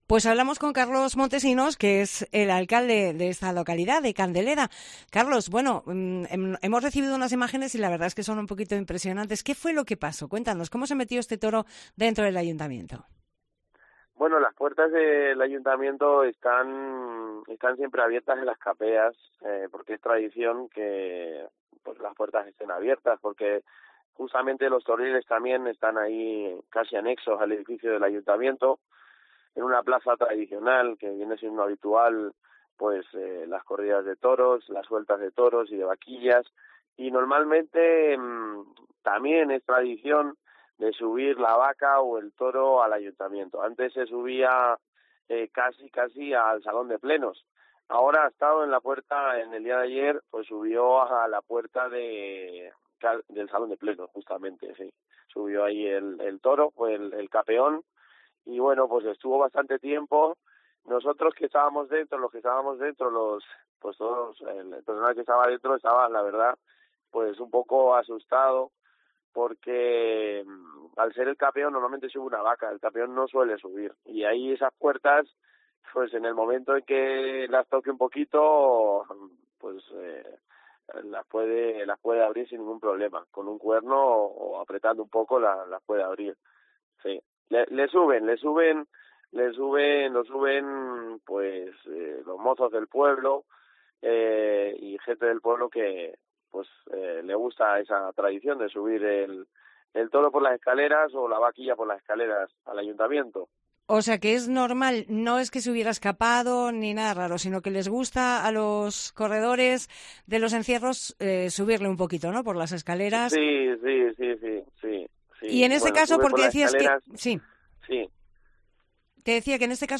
Entrevista a Carlos Montesinos, alcalde de Candeleda